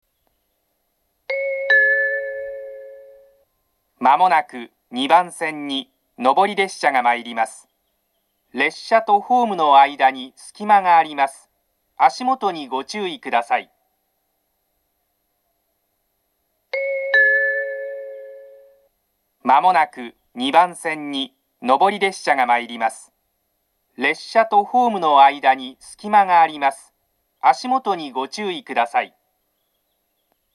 ２番線の接近放送は設定がおかしいのか、上り列車が来る際は下りと、下り列車が来る際は上りと放送していました（現在は正しく流れます）。
２番線上り接近放送
matsushima-2bannsenn-nobori-sekkinn2.mp3